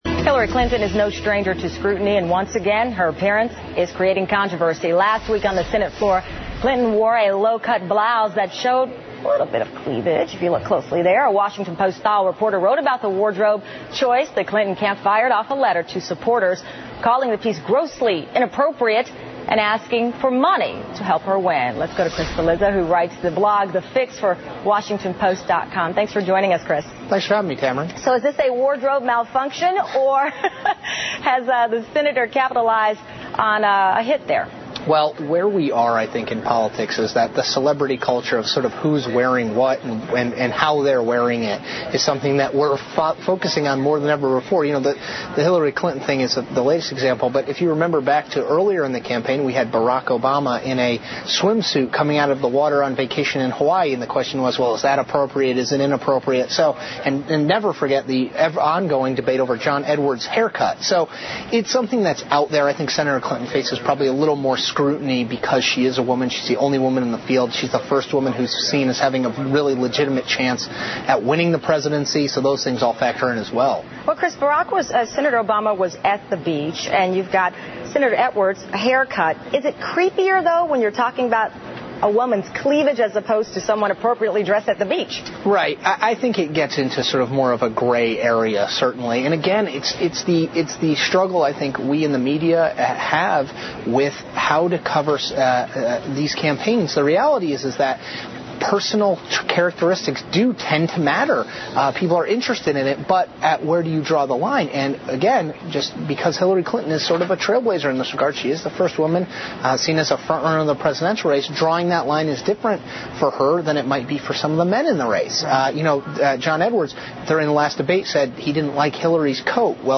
访谈录 Interview 2007-08-06&08-07, 希拉里的着装风波 听力文件下载—在线英语听力室